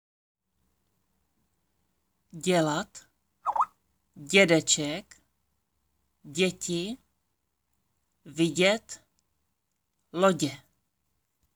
Tady si můžete poslechnout audio na výslovnost DĚ.
dě.m4a